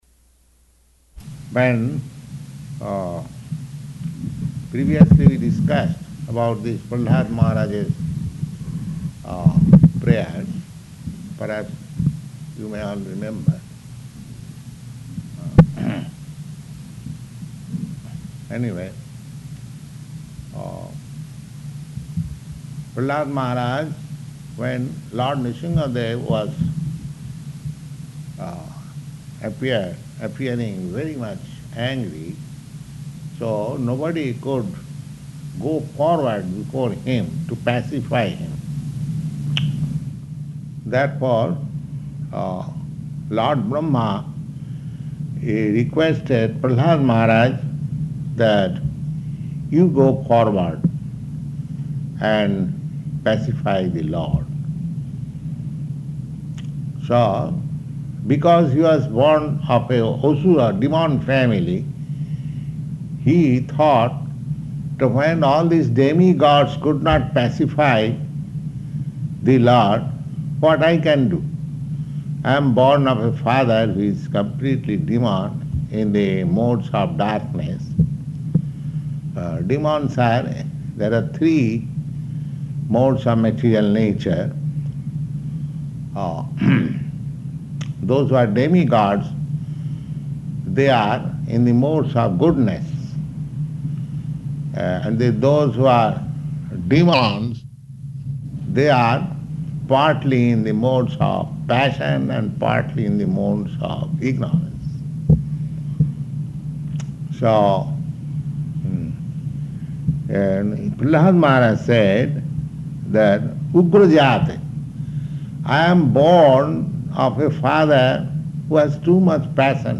Śrīmad-Bhāgavatam 7.9.8–10 --:-- --:-- Type: Srimad-Bhagavatam Dated: March 10th 1969 Location: Los Angeles Audio file: 690310SB-HAWAII.mp3 Prabhupāda: When previously we discussed about this Prahlāda Mahārāja's prayers…